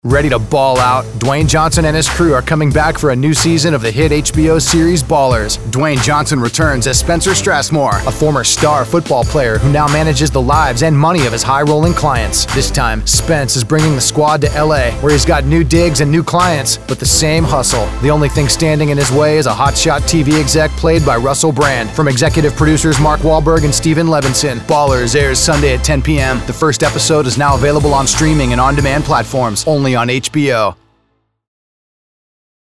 Male – Aggressive, Fast, Promo